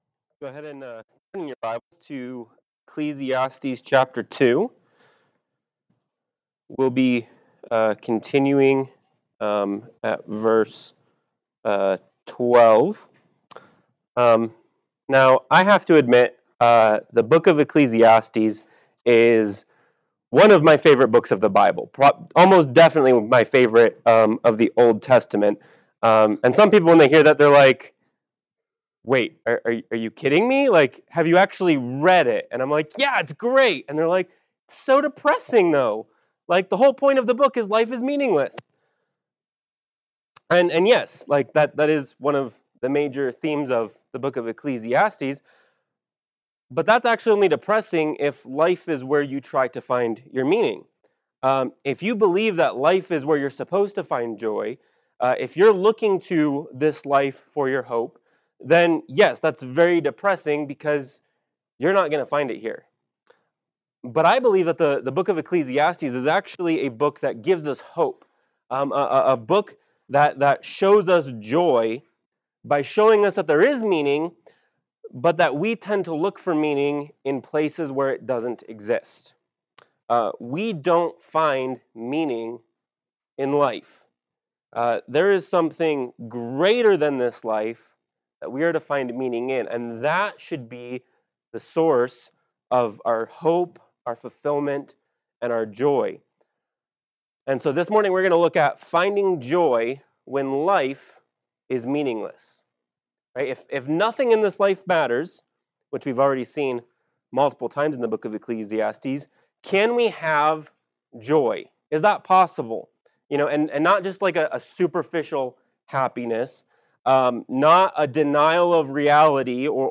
Note: The livestream audio is very quiet. The MP3 audio quality is much better.
Service Type: Sunday Morning Worship